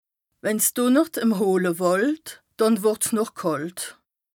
Bas Rhin
Ville Prononciation 67
Strasbourg